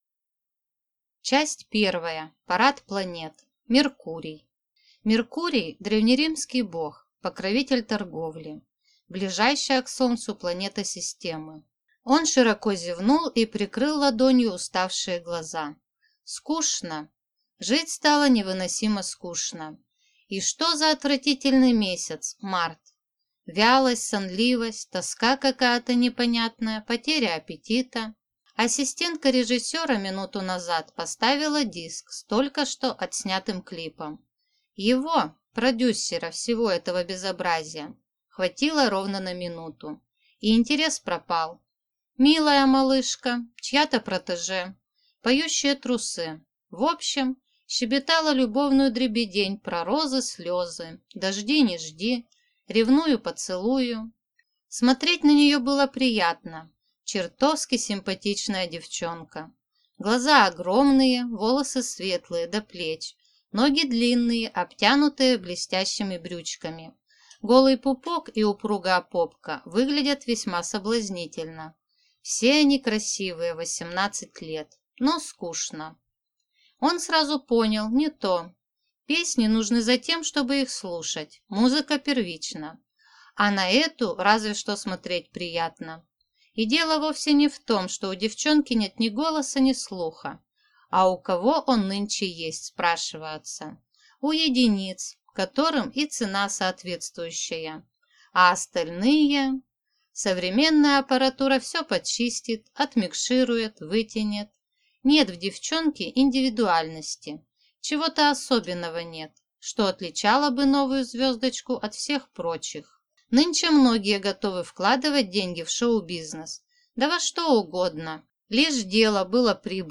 Аудиокнига И на небе есть Крест, или Ловушка для падающей звезды | Библиотека аудиокниг